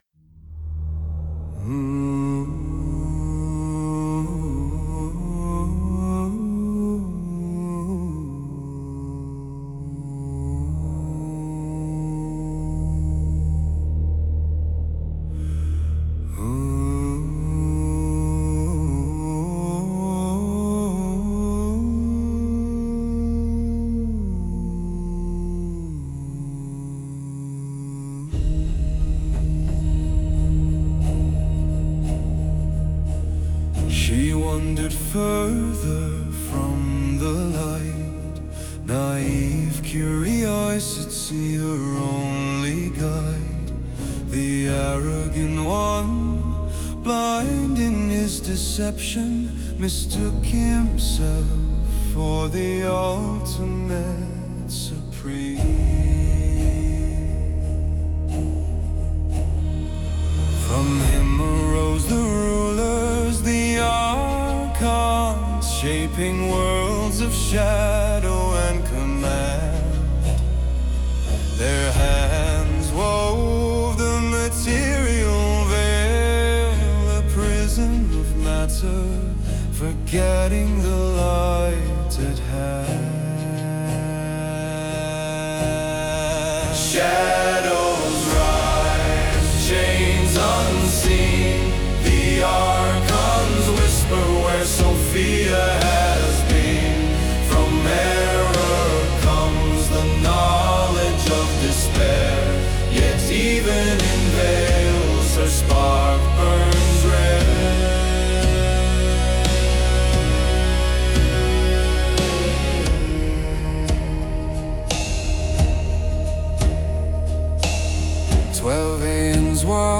Full album with 8 Songs produced in 432Hz